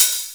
Closed Hats
SWHIHAT5.wav